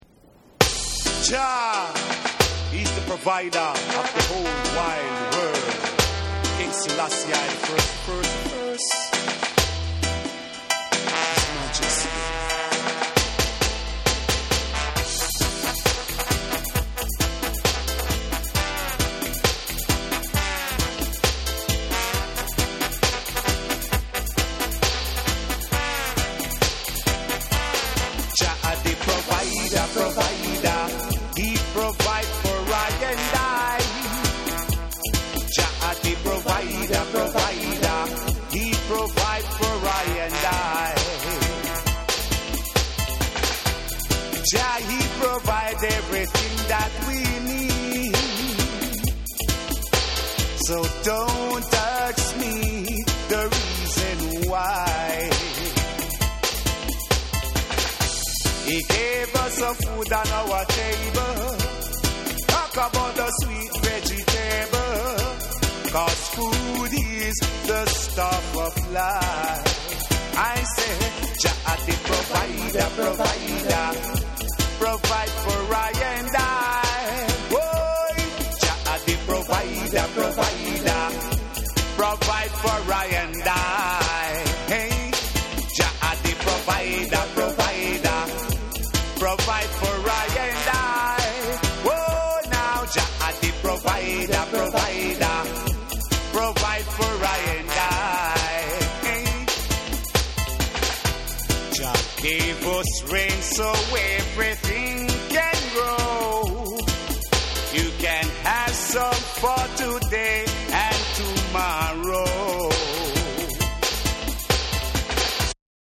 重圧なニュールーツ・トラックに男気のあるホーンやシンセと
REGGAE & DUB